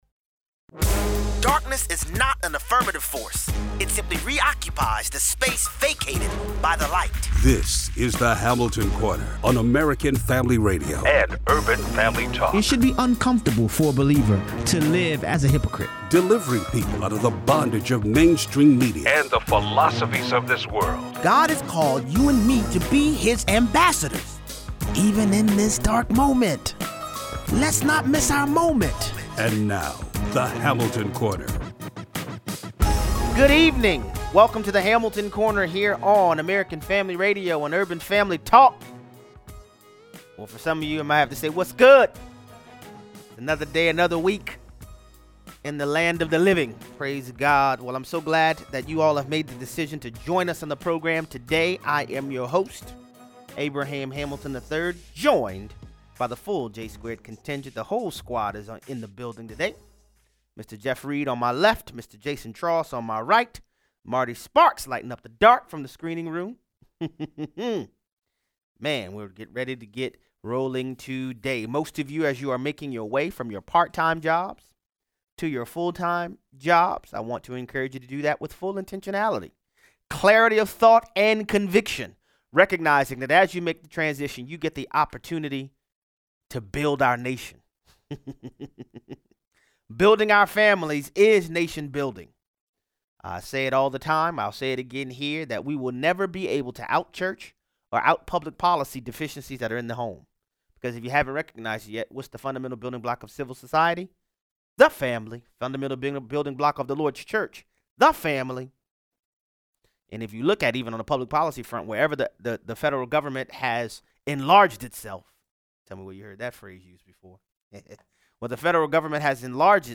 Better is one day in the LORD’s house than thousands elsewhere. 0:18 - 0:35: Helicopter crashes into New York City building. Jack Philipps is sued again! 0:38 - 0:55: President Trump strikes deal on immigration with Mexico. Callers weigh in!